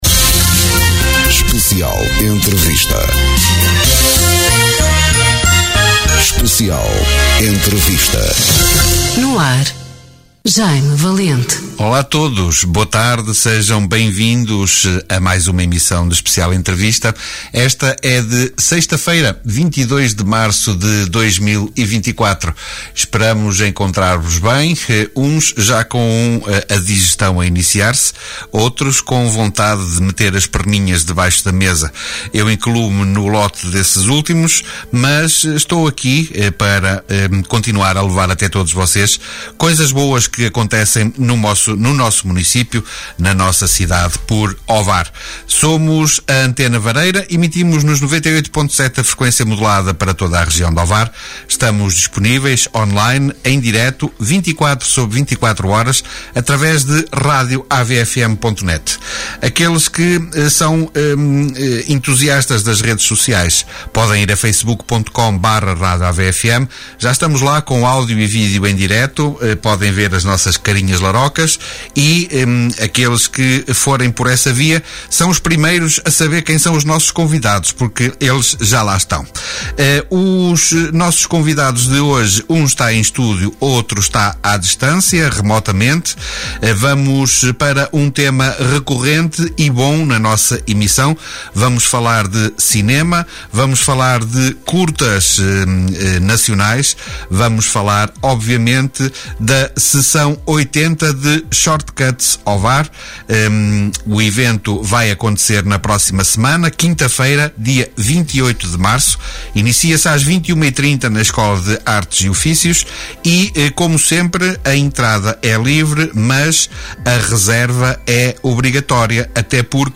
Especial Entrevista
Direitos reservados Especial Entrevista Conversas olhos nos olhos em direto Mais informações